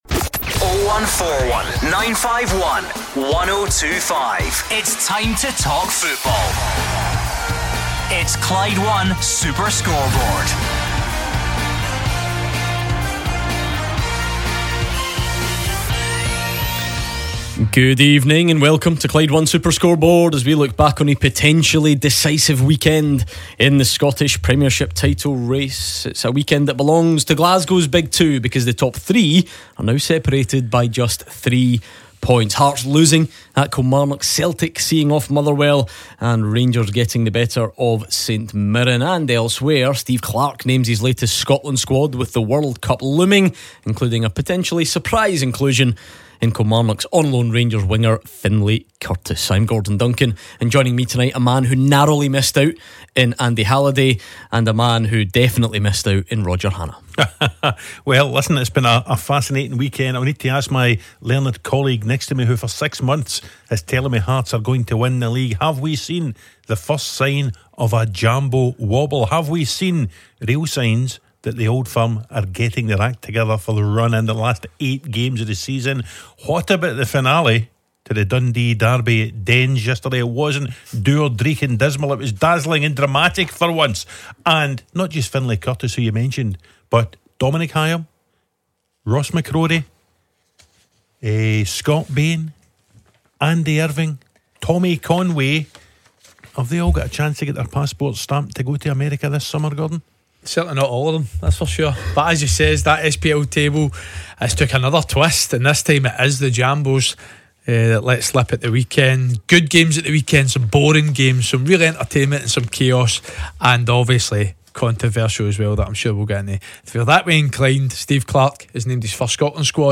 in the studio taking your reaction